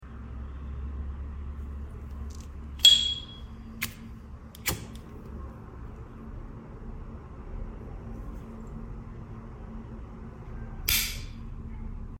Test âm thanh Dupont sơn sound effects free download
Test âm thanh Dupont sơn mài 2 lửa phiên bản limited